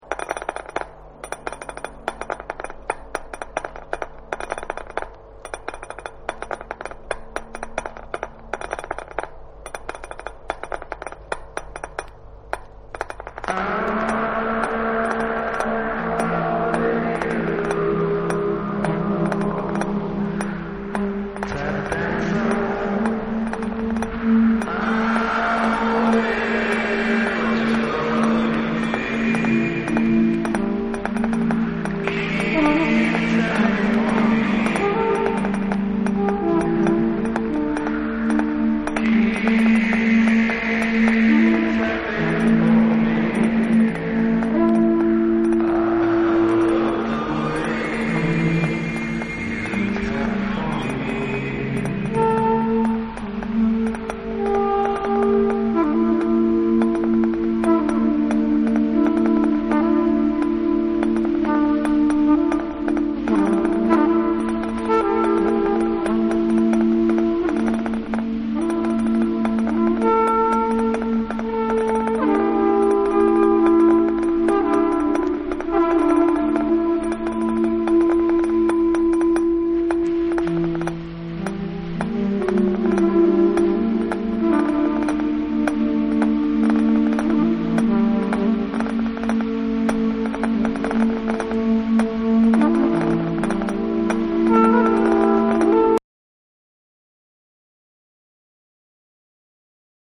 生楽器とエレクトロニクスが絶妙に融合された楽曲を中心に、エクスペリメンタル〜コンテンポラリーな楽曲まで幅広く収録。
BREAKBEATS